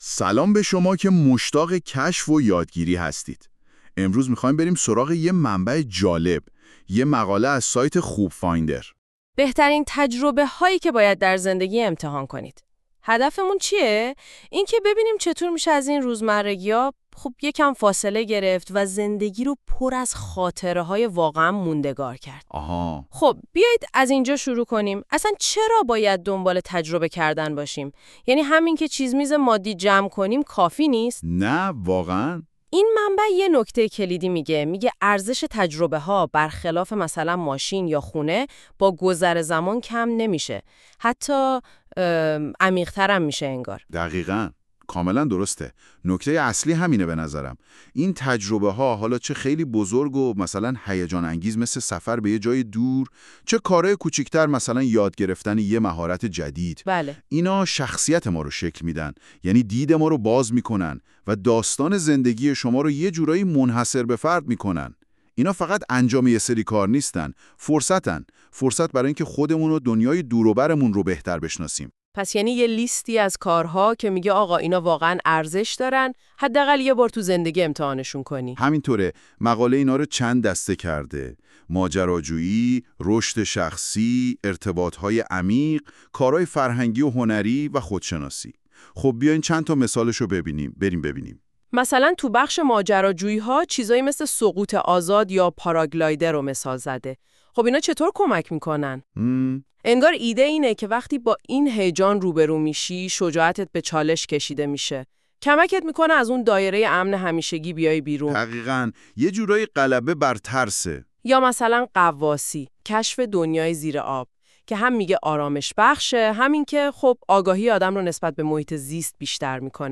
🎧 خلاصه صوتی بهترین تجربه هایی که باید در زندگی امتحان کنید
این خلاصه صوتی به صورت پادکست و توسط هوش مصنوعی تولید شده است.